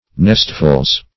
nestfuls - definition of nestfuls - synonyms, pronunciation, spelling from Free Dictionary Search Result for " nestfuls" : The Collaborative International Dictionary of English v.0.48: Nestful \Nest"ful\, n.; pl. Nestfuls . As much or many as will fill a nest.